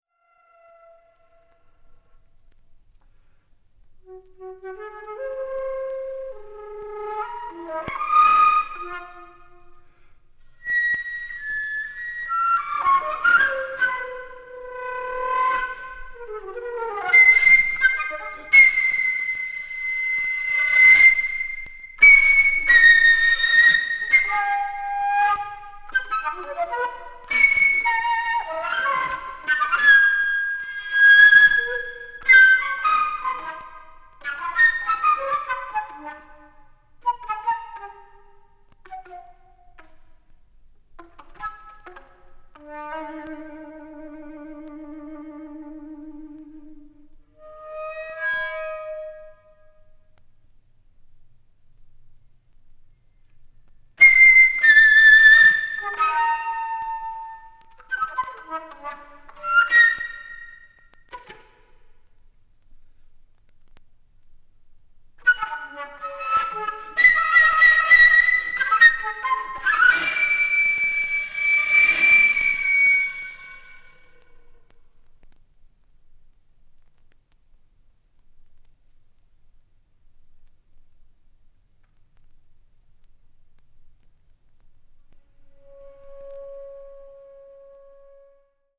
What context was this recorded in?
media : EX/EX,EX/EX,EX/EX(some slightly noises.)